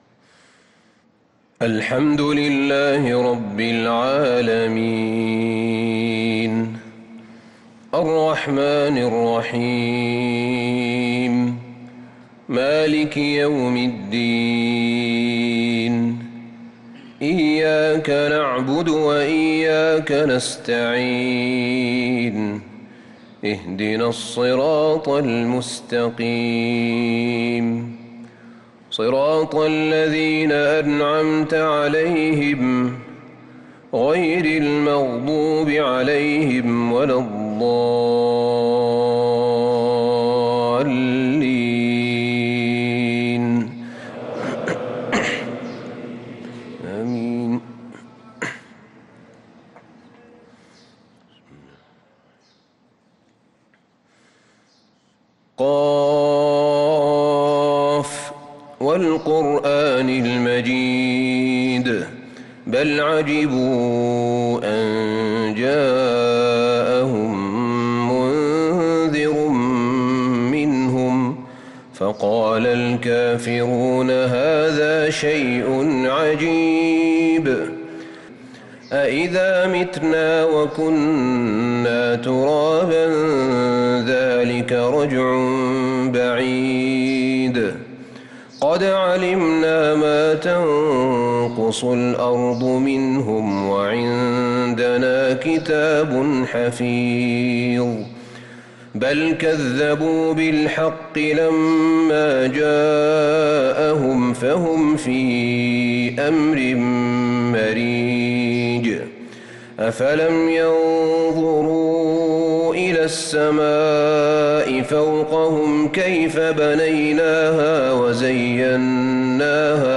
صلاة الفجر للقارئ أحمد بن طالب حميد 26 رمضان 1445 هـ
تِلَاوَات الْحَرَمَيْن .